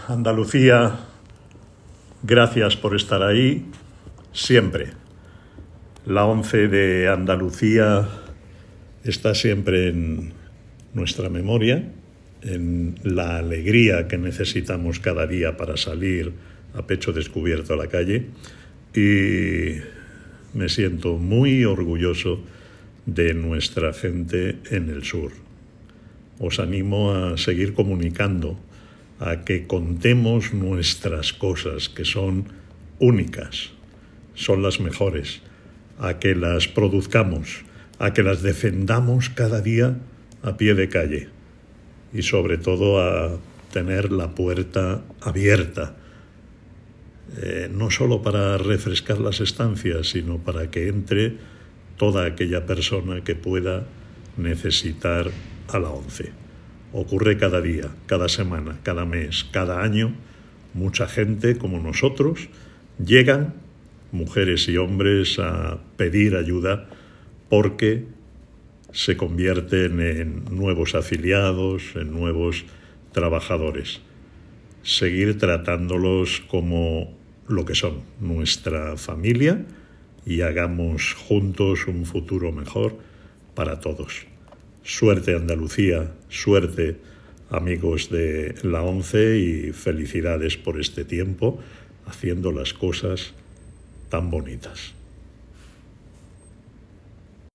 Audio del presidente del Grupo Social ONCE